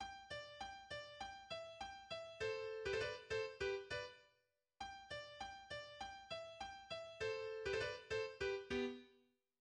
Genre Symphonie
2 hautbois
Les cors se taisent pendant le second mouvement.
, en sol majeur, 73 mesures, 2 sections répétées deux fois (mesures 1 à 35, mesures 36 à 73)
Introduction de l'Andante: